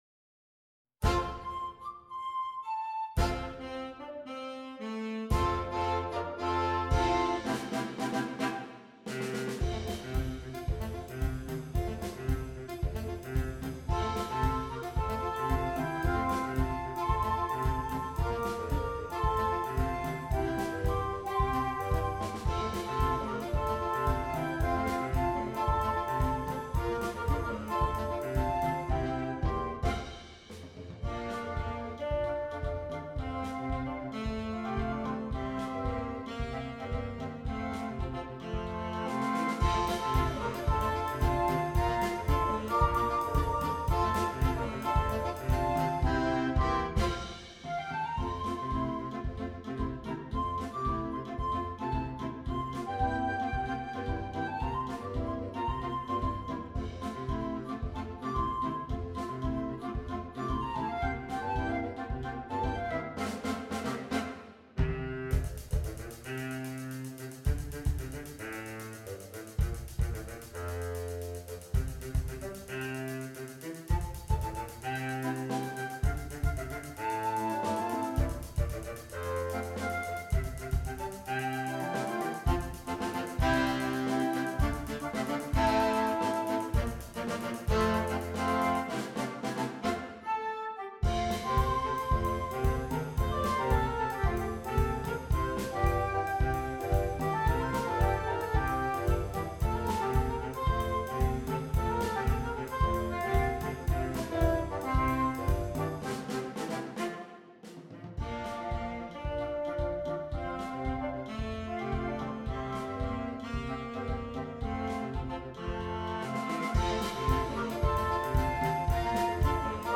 • Interchangeable Woodwind Ensemble